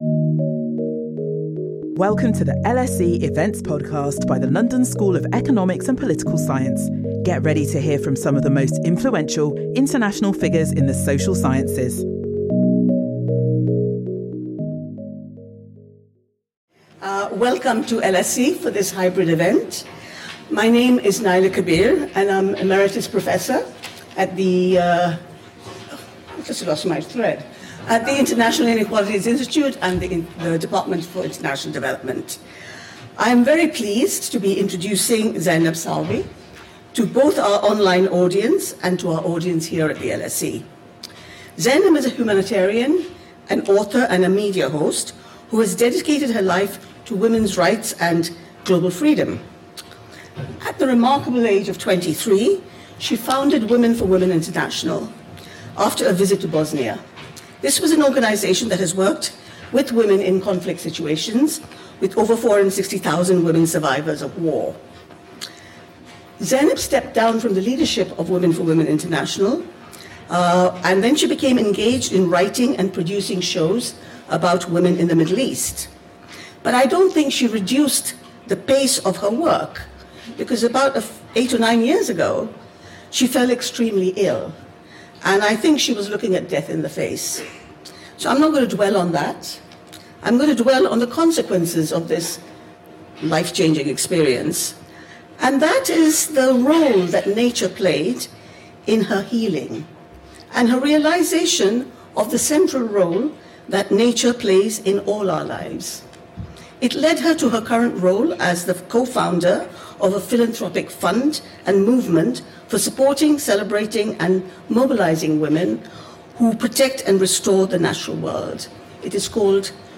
Climate change is not gender-neutral — not in its impact on women and girls, nor in the solutions women are leading. This lecture will share new research revealing how women’s leadership is providing new pathways to address the climate crisis.